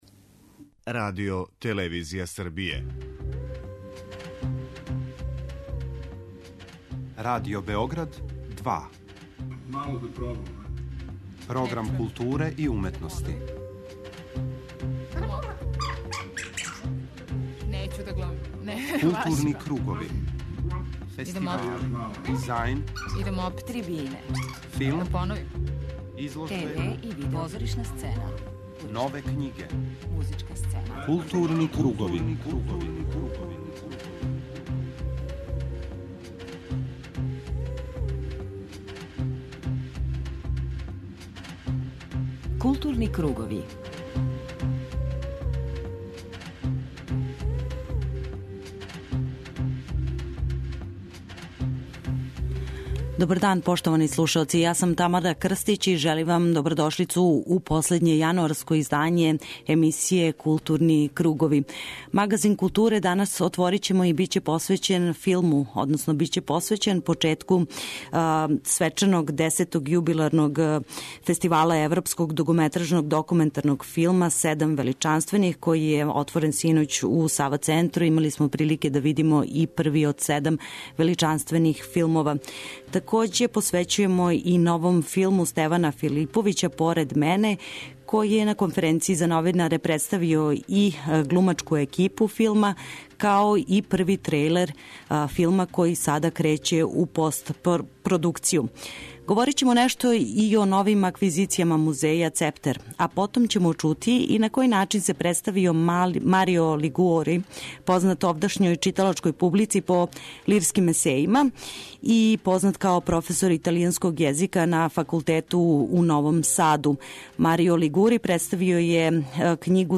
преузми : 51.15 MB Културни кругови Autor: Група аутора Централна културно-уметничка емисија Радио Београда 2.